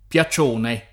piacione [ p L a ©1 ne ]